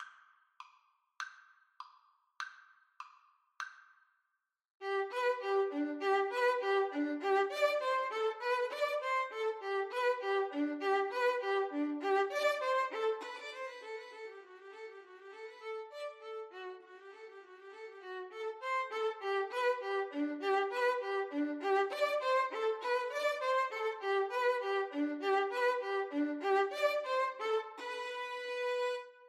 Violin 1Violin 2
2/4 (View more 2/4 Music)
Allegro (View more music marked Allegro)
Classical (View more Classical Violin Duet Music)